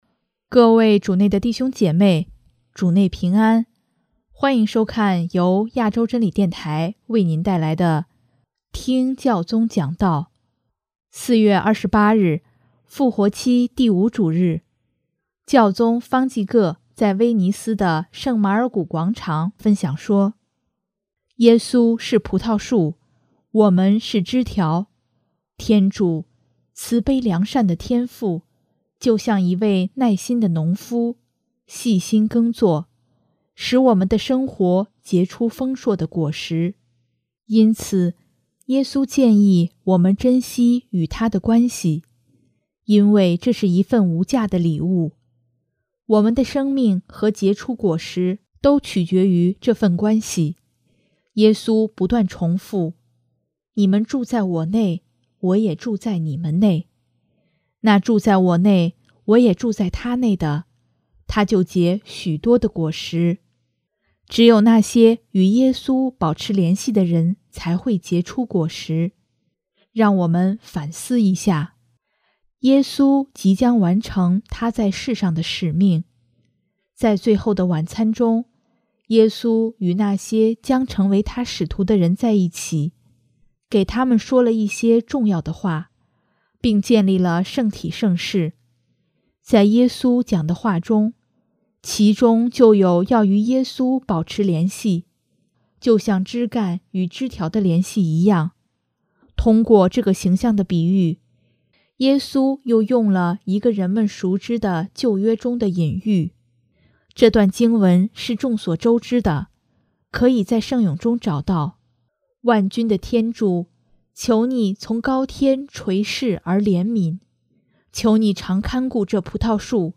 4月28日，复活期第五主日，教宗方济各在威尼斯的圣马尔谷广场分享说：